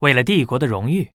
文件 文件历史 文件用途 全域文件用途 Bhan_amb_01.ogg （Ogg Vorbis声音文件，长度1.1秒，128 kbps，文件大小：18 KB） 源地址:游戏中的语音 文件历史 点击某个日期/时间查看对应时刻的文件。